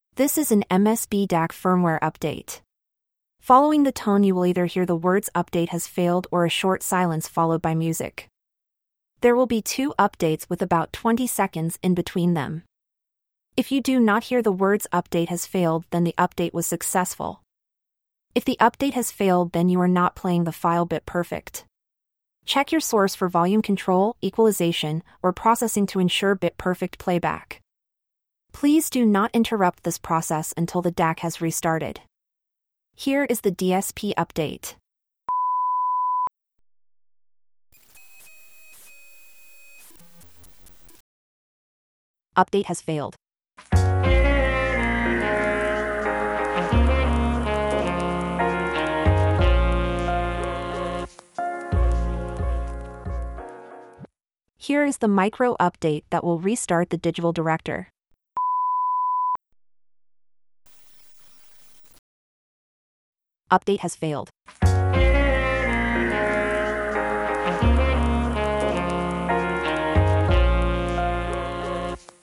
When you play the file you will hear instructions and two upgrade tones. Following each tone you will either hear silence for about 30 seconds (this varies) or you will hear the message ‘upgrade failed’.